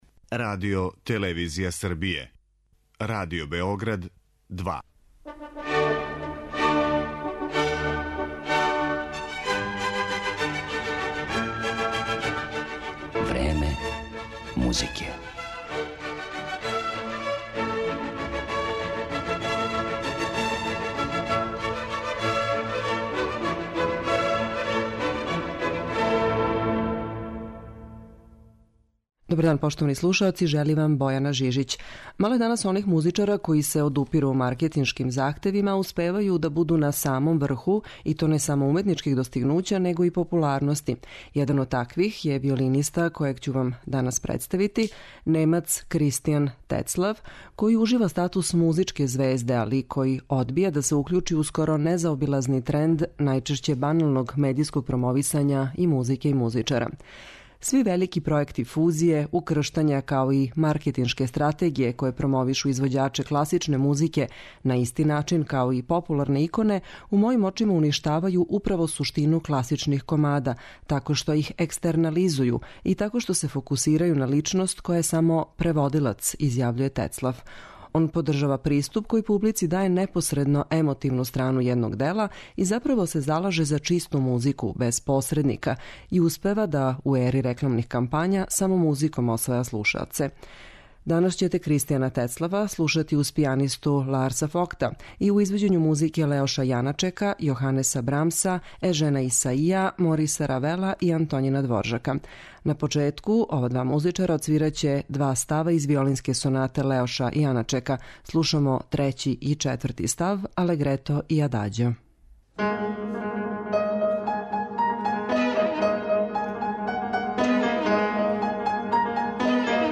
виолински виртуоз
пијанистом